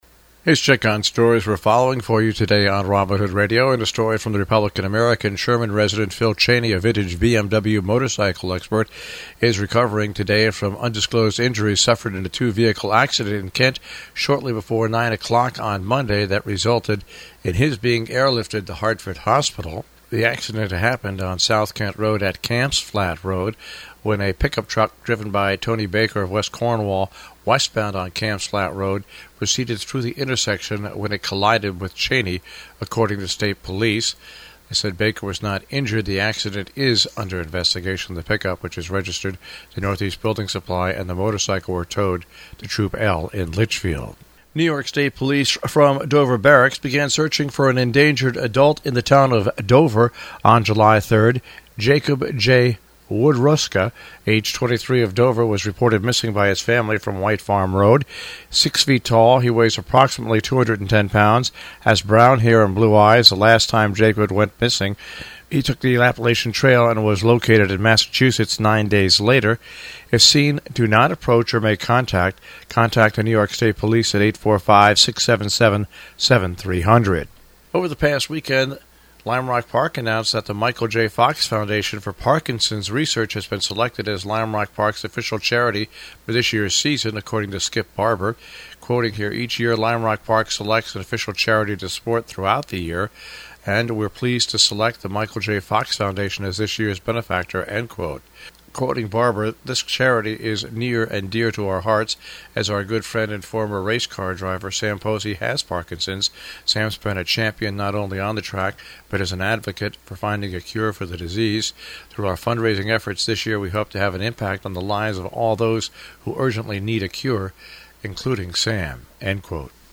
covers news and events in the Tri-State Region on The Breakfast Club on Robin Hood Radio